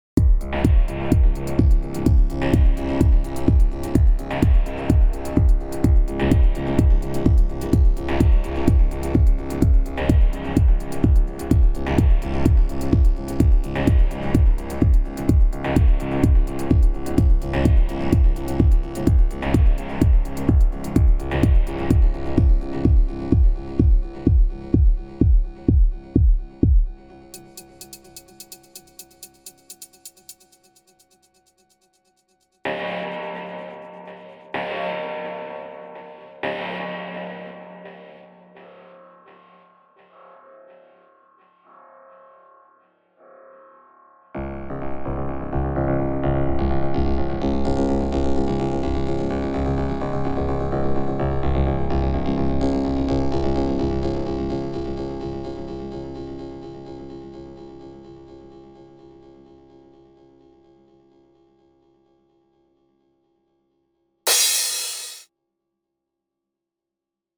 I was curious to see how much you could twist one sound, so took a 909 crash from the stock library and created a techno loop – kick, hi hat, stab, ambience and a synthy thing – from it.
I play the loop, then the individual tracks soloed, then the crash at the end.